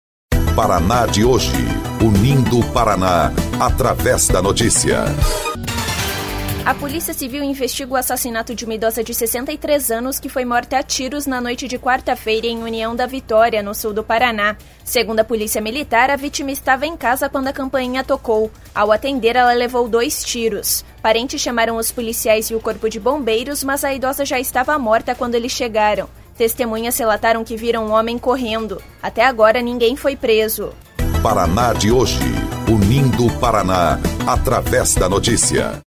BOLETIM - Polícia investiga morte de idosa em União da Vitória